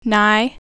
nigh  n
as in nigh, night, no, ten
nigh.wav